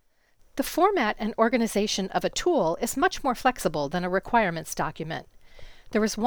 When I amplified the sound, it sounded like my kind of pop, but it’s much fainter than some of the others.
That noise sounds mechanical to me, rather than digital : if that is the case changing the CPU priority won’t help.